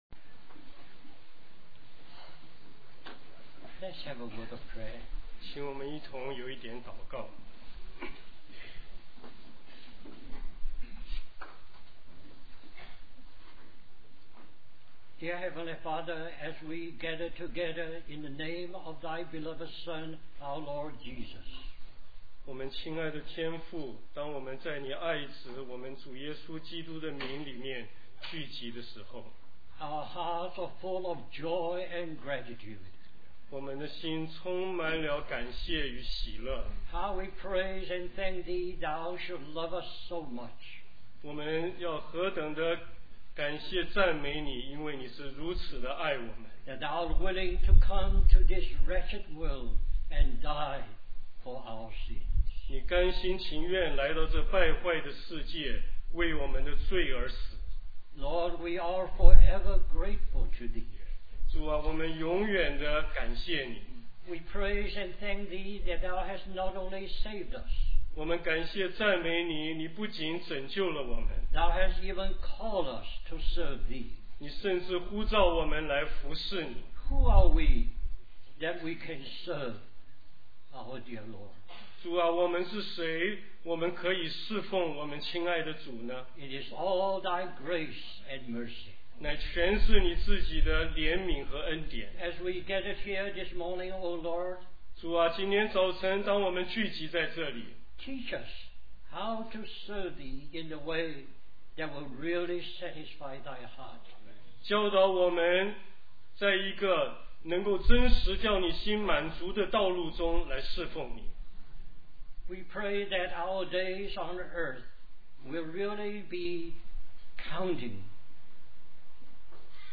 A collection of Christ focused messages published by the Christian Testimony Ministry in Richmond, VA.
Special Conference For Service, Australia